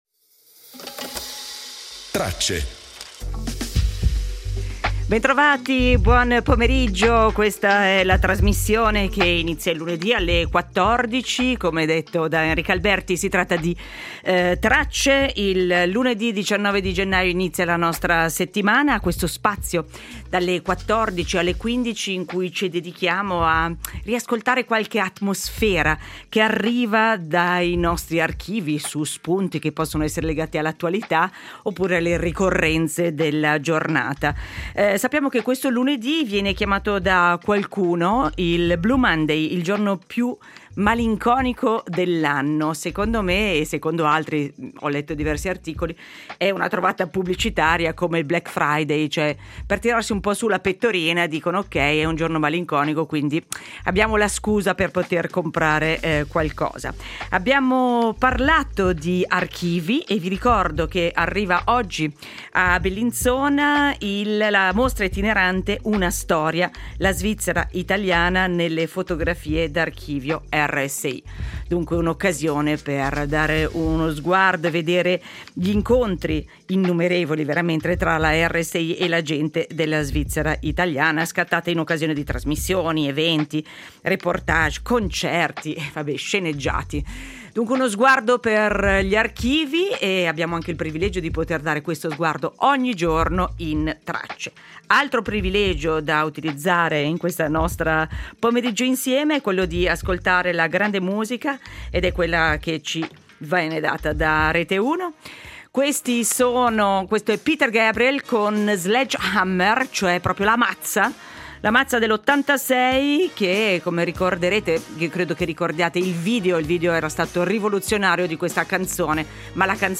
Chiudiamo con l’enigma del cubo di Rubik e con un’intervista del 1982 al suo inventore, l’architetto ungherese Ernö Rubik.